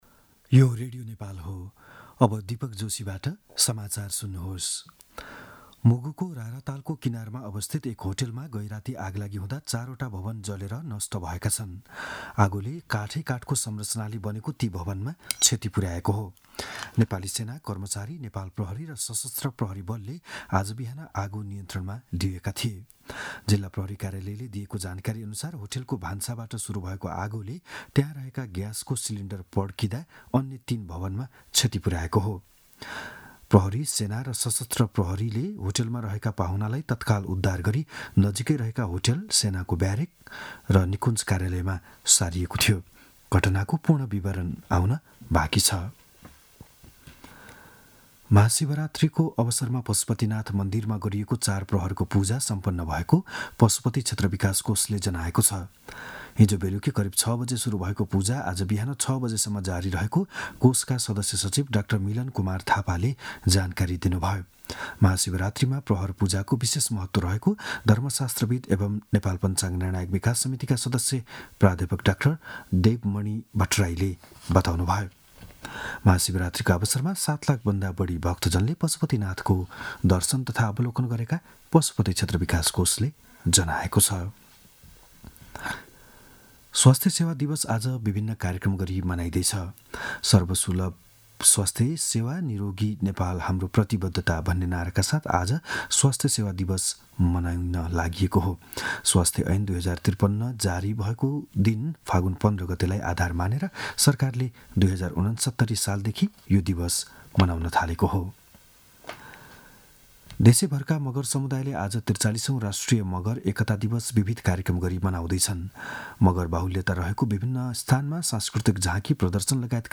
बिहान ११ बजेको नेपाली समाचार : १६ फागुन , २०८१